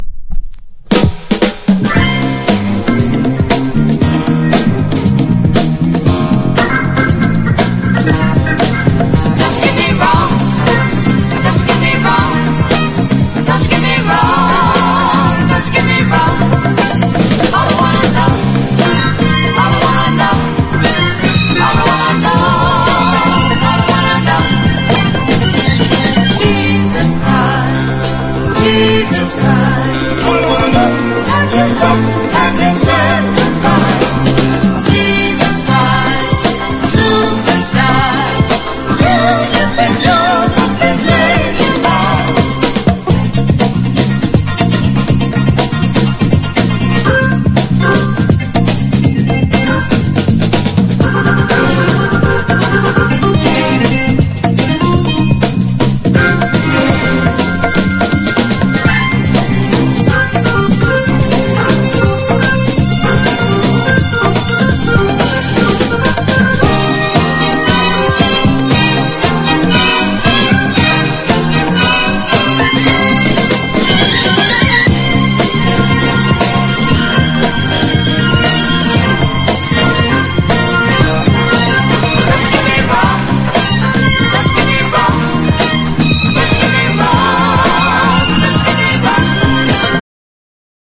1. 70'S ROCK >
RHYTHM & BLUES# RARE GROOVE